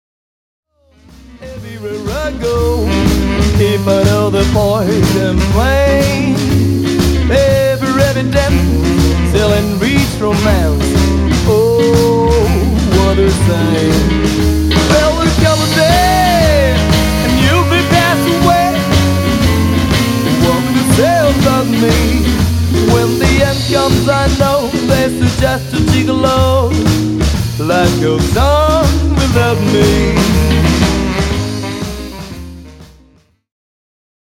ジャズ、ジャイヴ、スウィング、ボサノヴァといったスタイルに敬意を払いつつも、結果的にはロックなサウンドに仕上がっている。